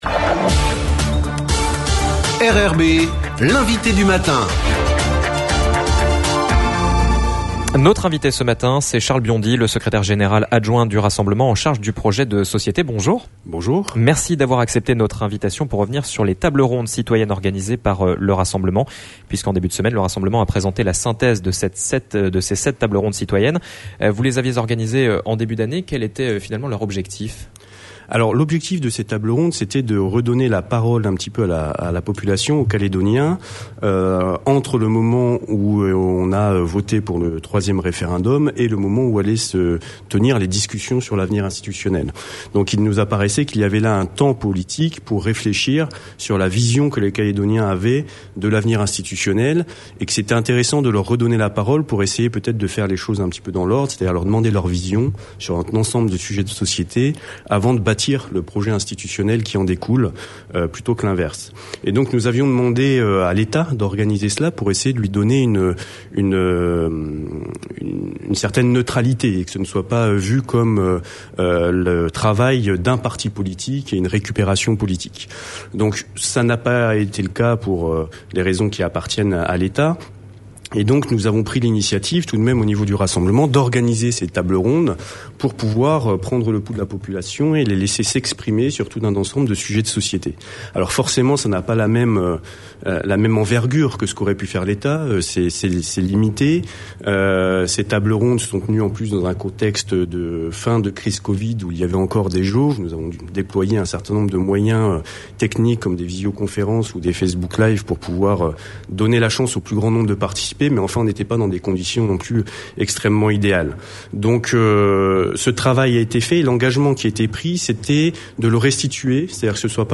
INVITE DU MATIN